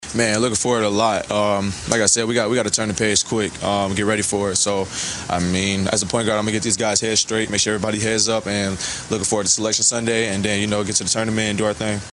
That’s ISU coach T.J. Otzelberger. The Cyclones were outscored 23-0 in fast break points.